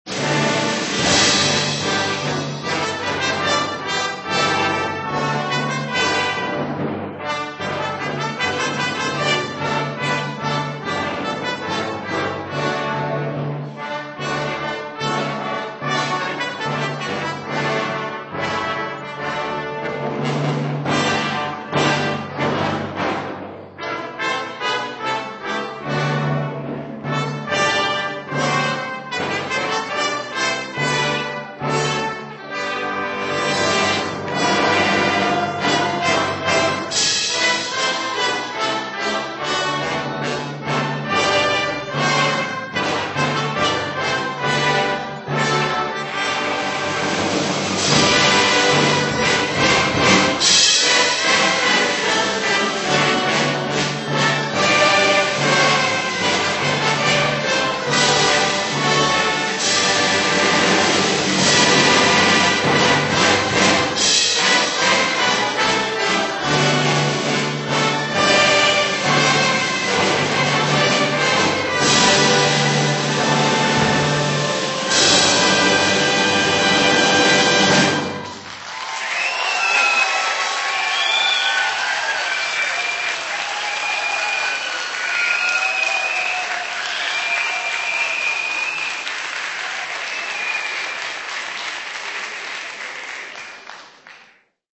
Concert on Sunday 25th June 2000
Peel Hall, Salford University